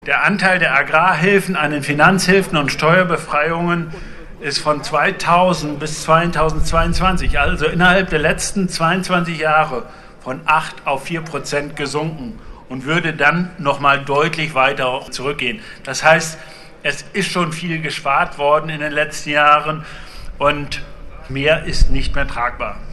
O-Ton Nachrichten